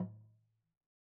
LogDrumLo_MedM_v1_rr1_Sum.wav